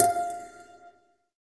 SYN_Pizz7.wav